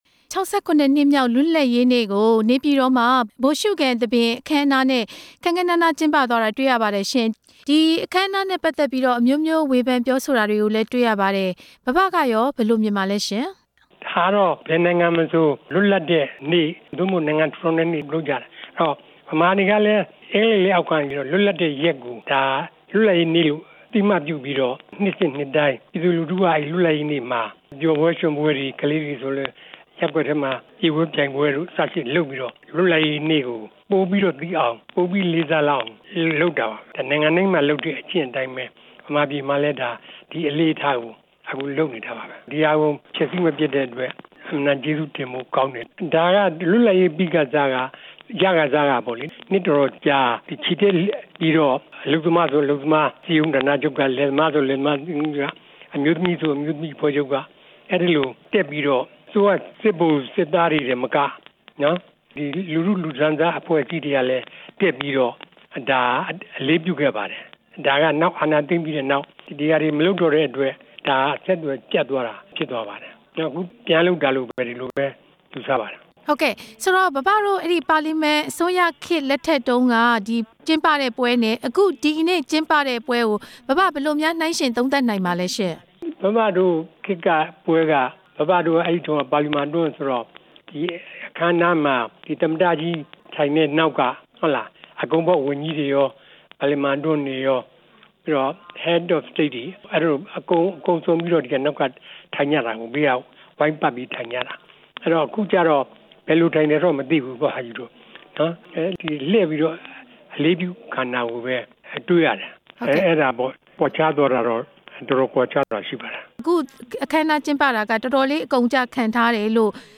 ၆၇ နှစ်မြောက် လွတ်လပ်ရေးနေ့ ဗိုလ်ရှုခံ အခမ်းအနားအကြောင်း သခင်ချန်ထွန်းနဲ့ မေးမြန်းချက်